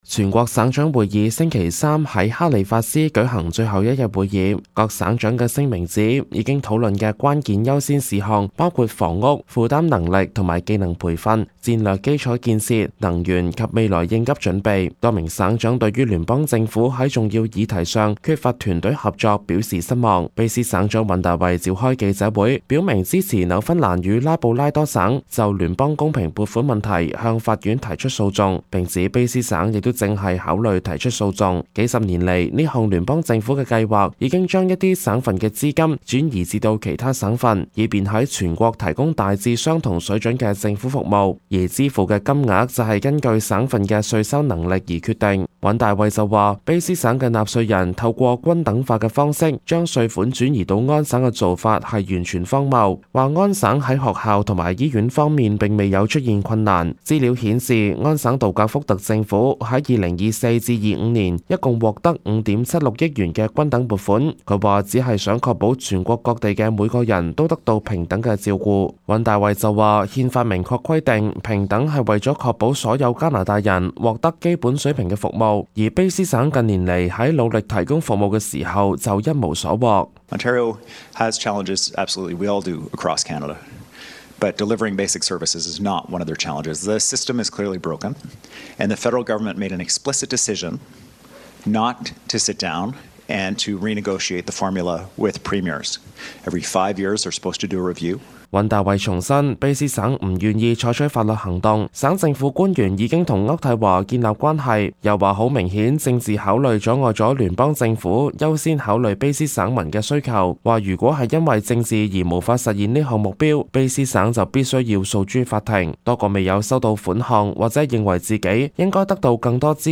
粵語：